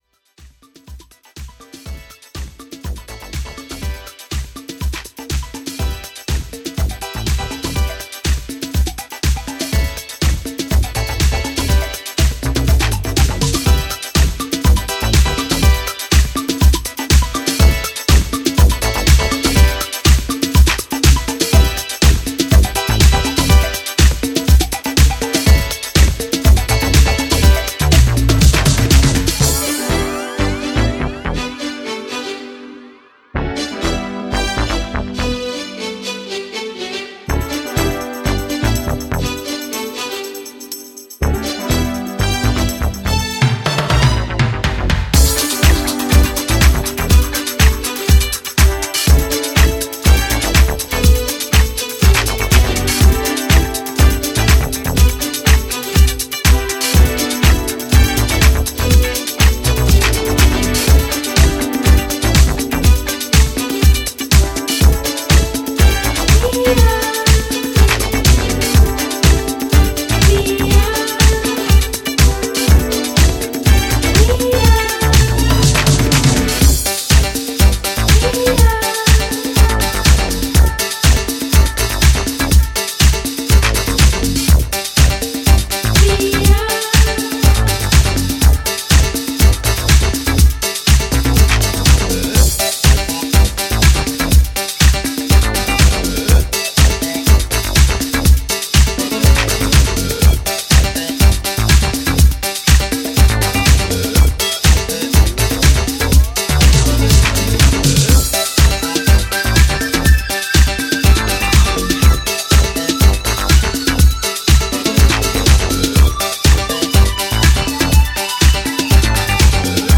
Munich neo disco duo